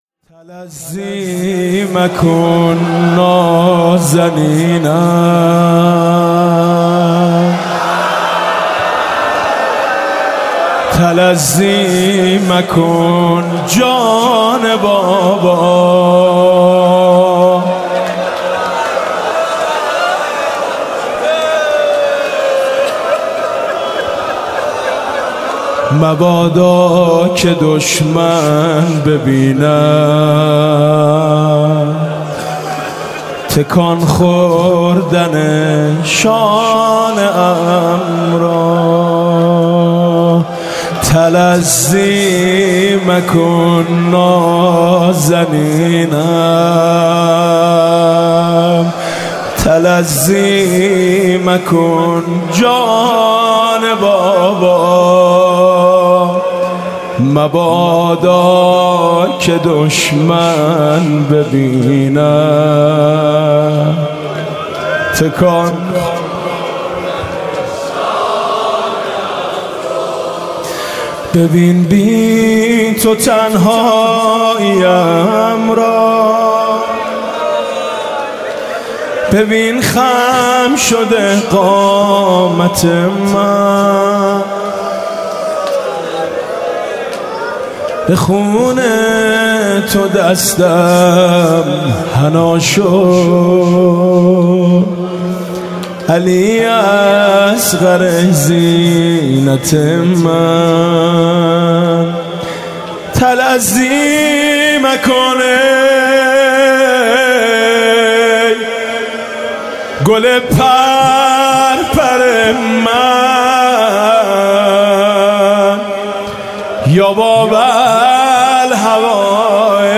شب هفتم محرم 95_زمزمه_تلظی مکن نازنینم